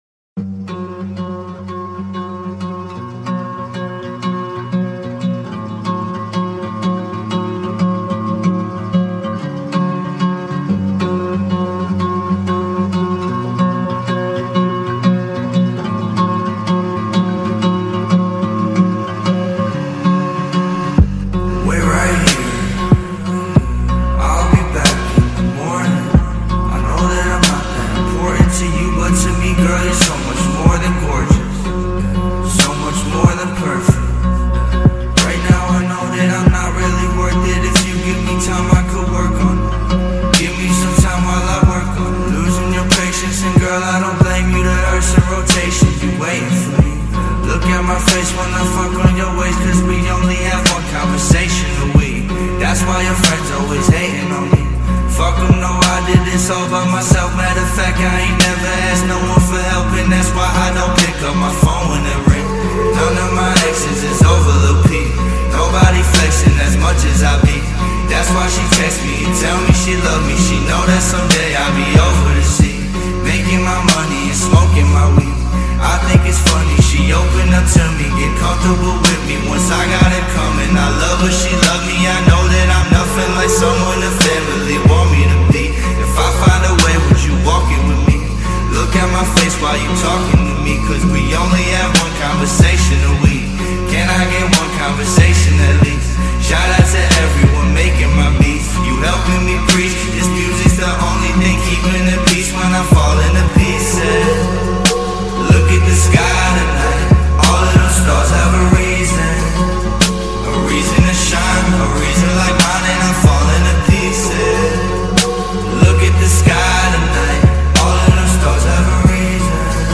2024-08-08 17:13:53 Gênero: Axé Views